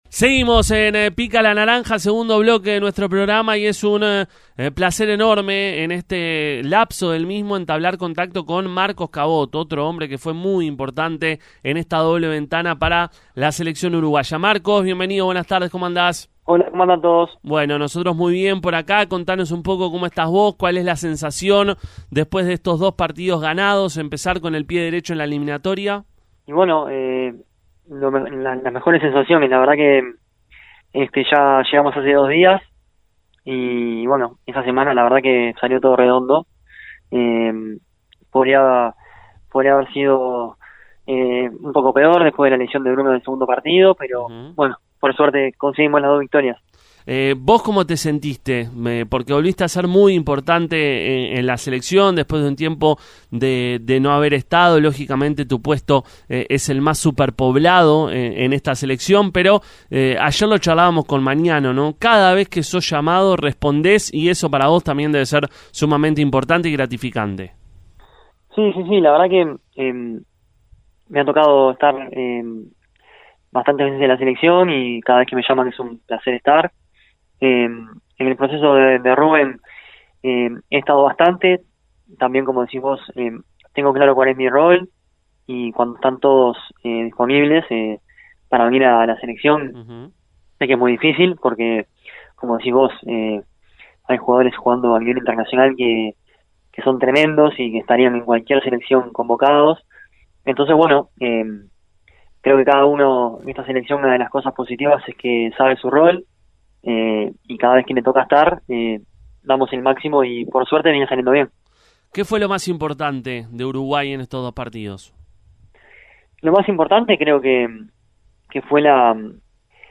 Marcos Cabot, jugador de la selección uruguaya y de Defensor Sporting, habló en el programa Pica la Naranja sobre esta ventana de eliminatorias con Uruguay.